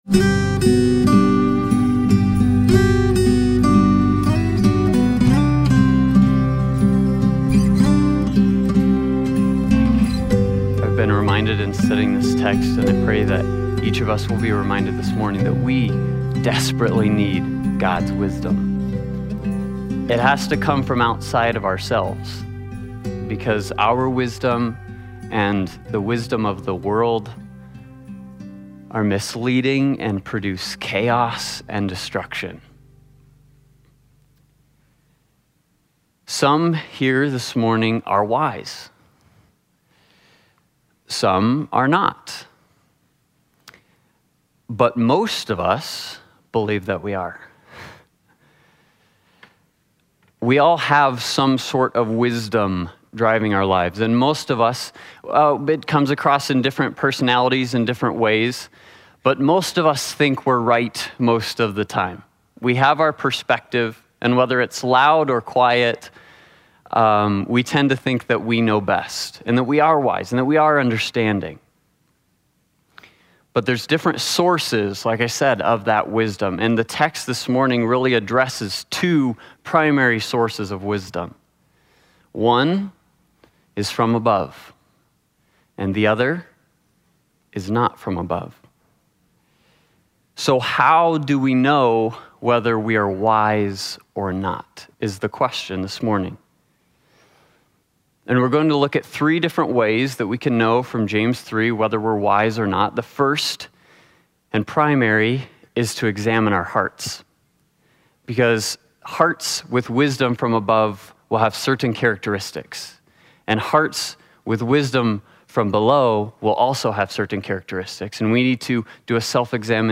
James 3:13-18 Service Type: Sunday Morning Worship Topics